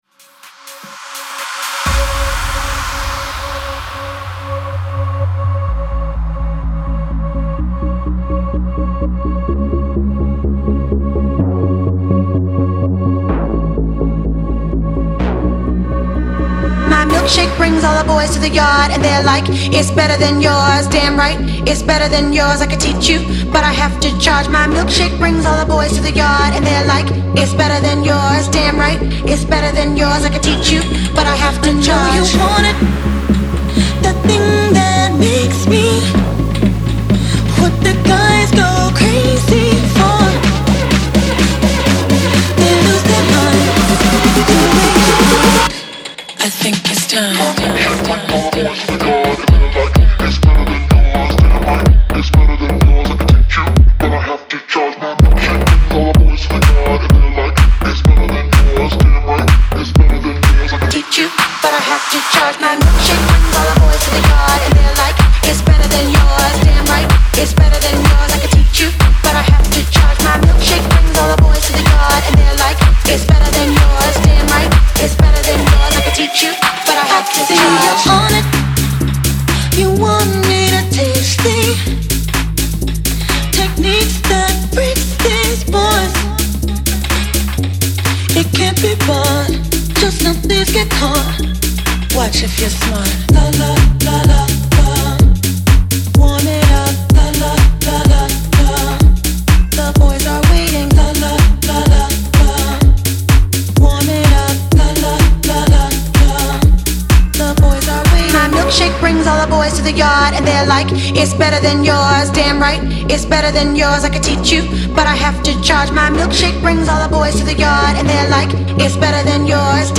динамичная композиция в жанре поп/электроника